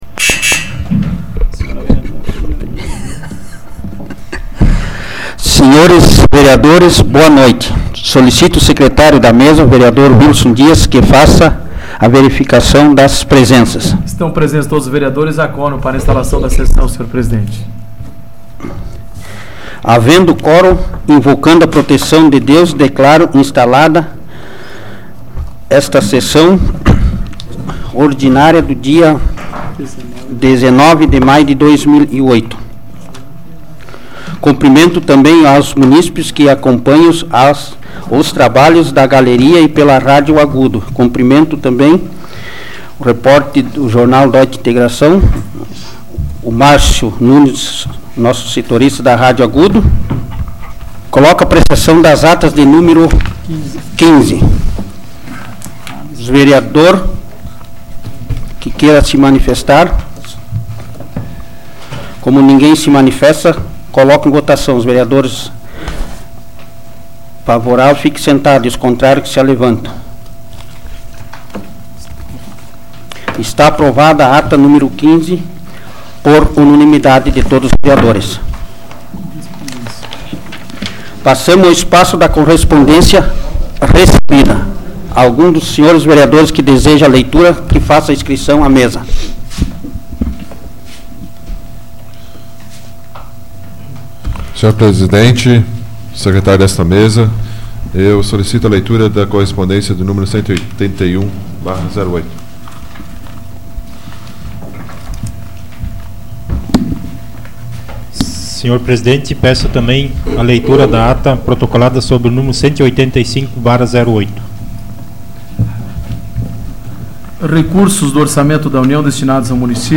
Áudio da 122ª Sessão Plenária Ordinária da 12ª Legislatura, de 19 de maio de 2008